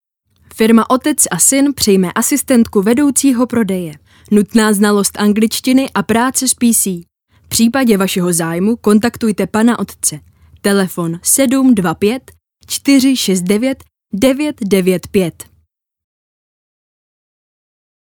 Ženský voiceover do reklamy / 90 sekund
Hledáte do svého videa příjemný ženský hlas?
Nahrávání probíhá v profesionálním studiu a výsledkem je masterovaná audio stopa ve formátu WAV, ořezaná o nádechy a další rušivé zvuky.